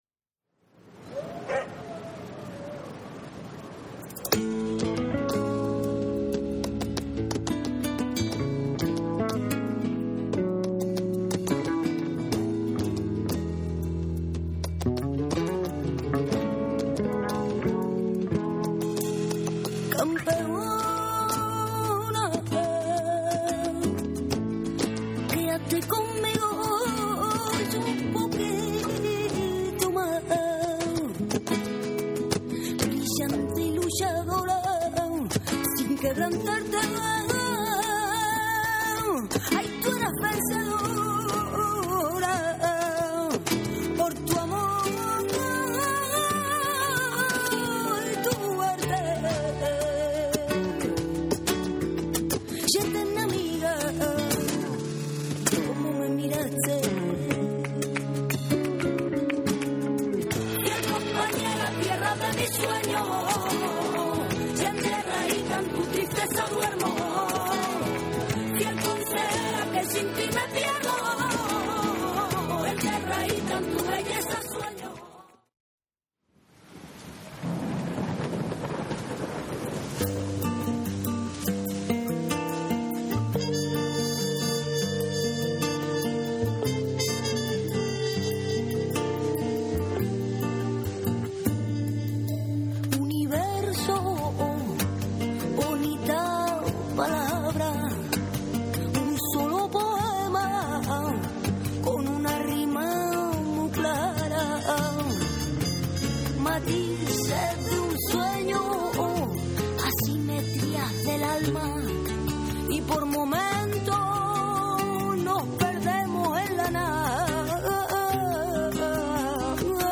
フラメンコギターにホーンも混ざる哀愁漂う切ないメロディーが印象的な2。
カホン、パルマ（手拍子）、ピアノの演奏
WORLD / NEW RELEASE / CD